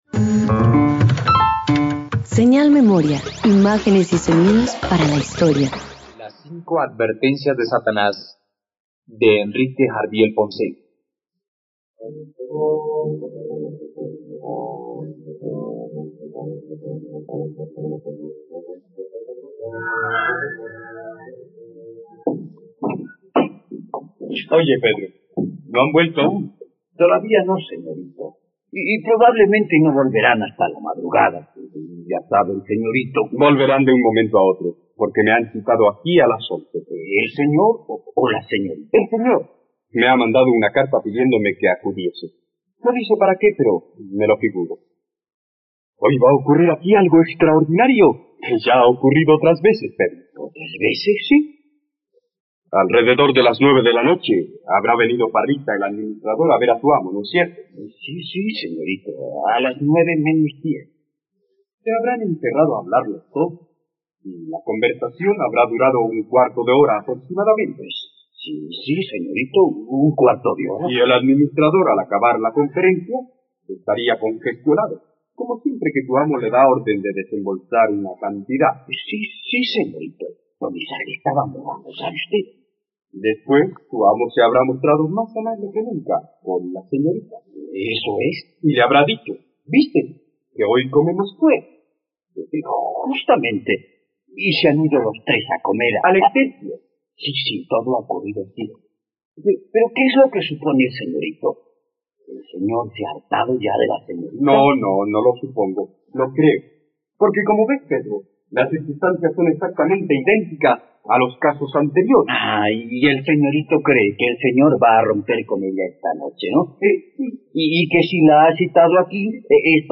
Las 5 advertencias de Satanás - Radioteatro dominical | RTVCPlay